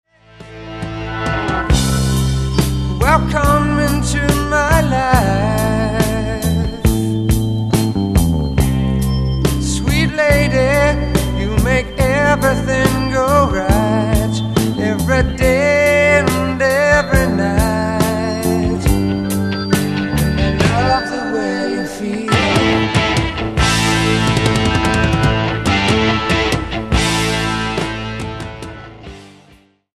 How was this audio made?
Recorded at Soundstage, Toronto.